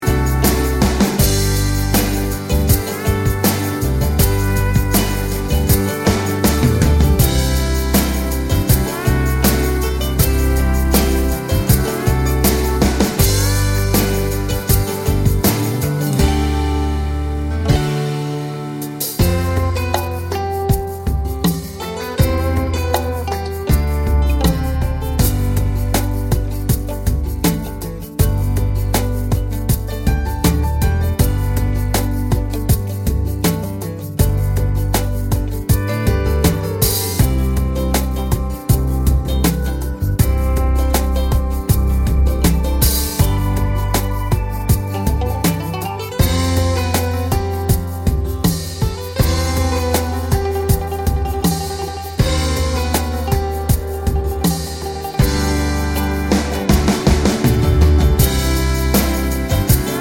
no Backing Vocals Country (Female) 3:45 Buy £1.50